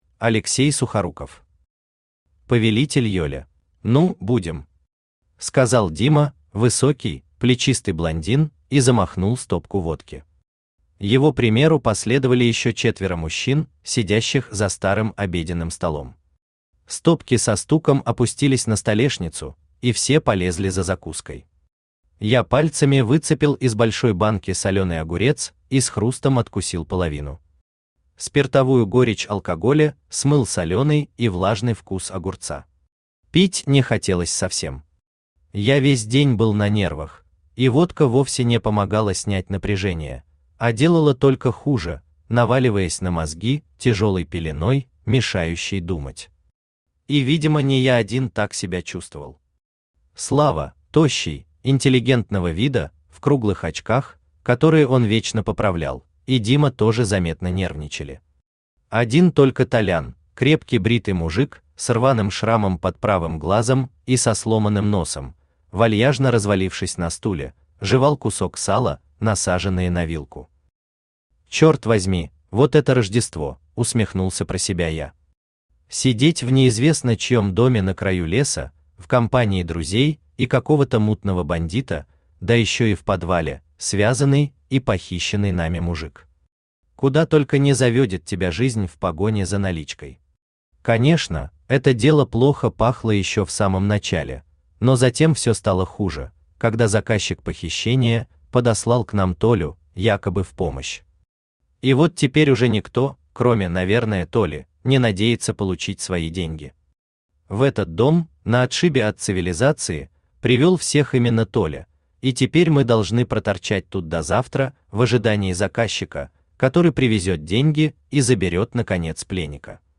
Аудиокнига Повелитель Йоля | Библиотека аудиокниг
Aудиокнига Повелитель Йоля Автор Алексей Михайлович Сухоруков Читает аудиокнигу Авточтец ЛитРес.